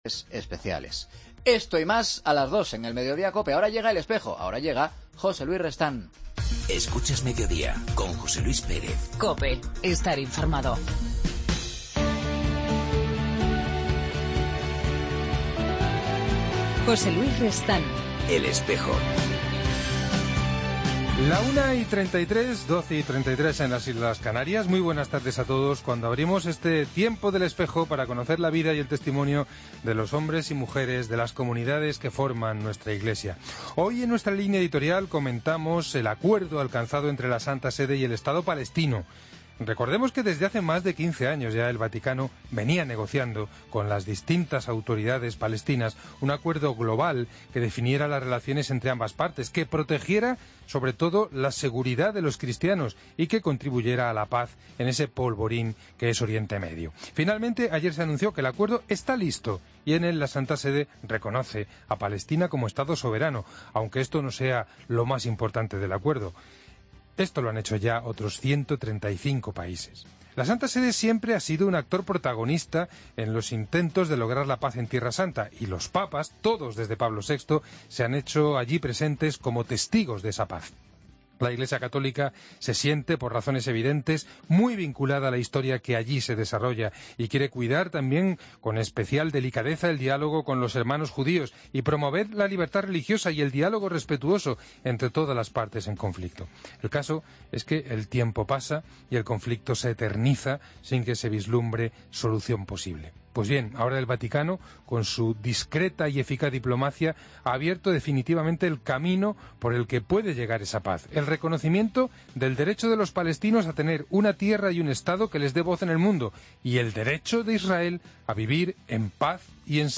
AUDIO: Hoy conversamos con el Arzobispo de Oviedo, Mons. Jesús Sanz, con el que recordaremos la reciente marcha de jóvenes de la diócesis...